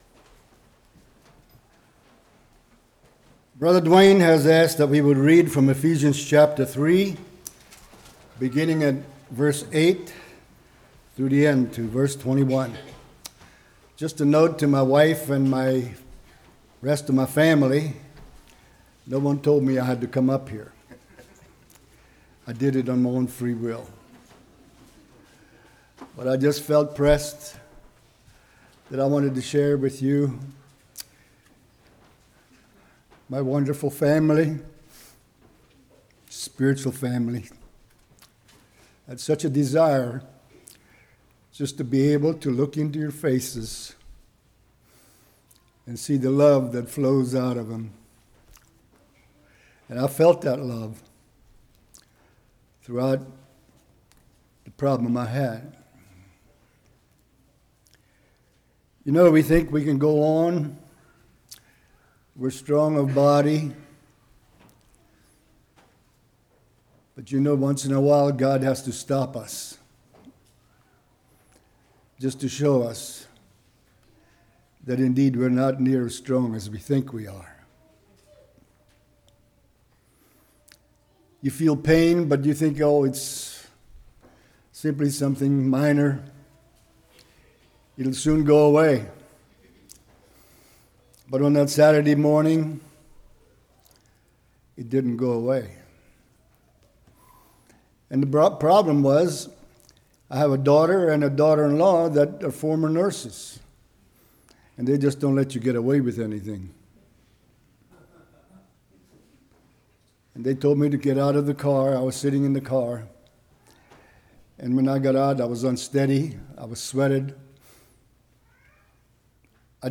Ephesians 3:8-21 Service Type: Morning What if I Communed More With God in Reading His Word & Prayer?